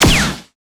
POWGSHOT01.wav